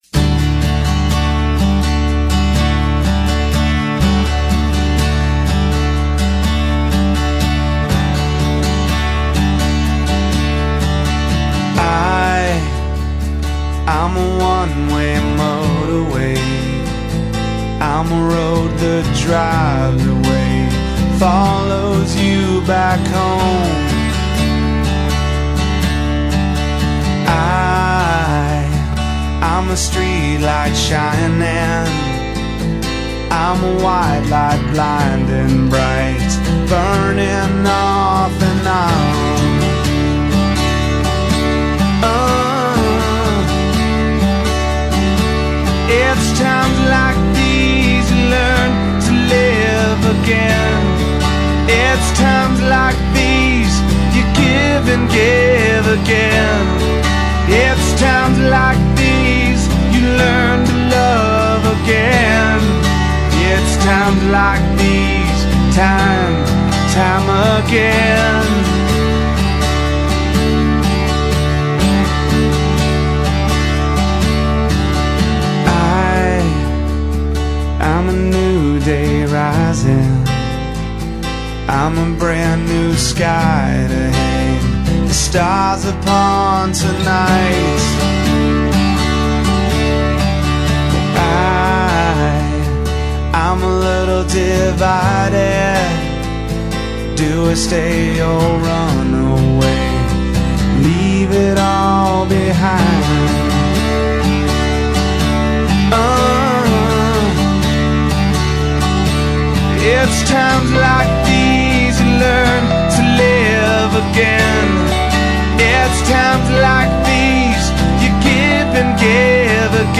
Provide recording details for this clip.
I discovered a real gem when I found the acoustic version.